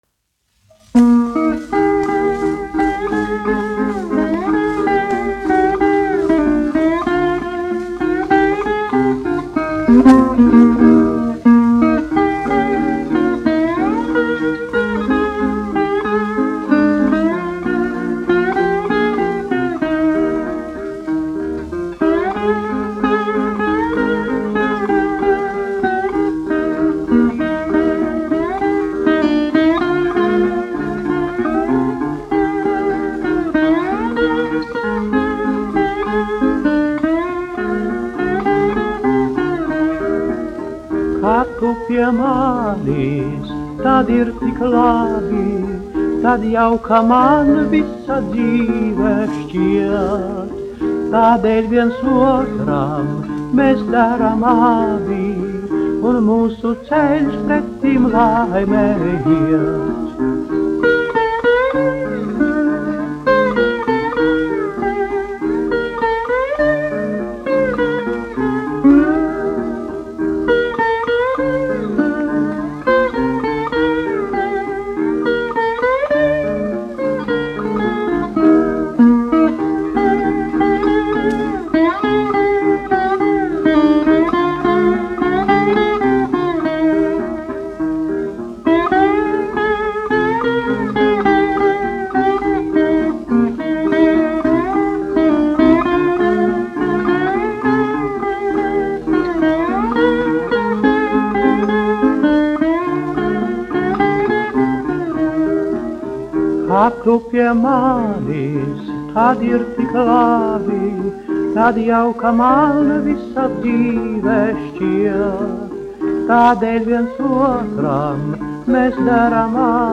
1 skpl. : analogs, 78 apgr/min, mono ; 25 cm
Fokstroti
Populārā mūzika
Latvijas vēsturiskie šellaka skaņuplašu ieraksti (Kolekcija)